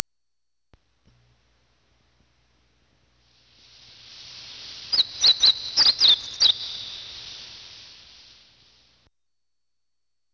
ホオジロ（京阪電鉄株式会社提供）
oto_guidehojiro.wav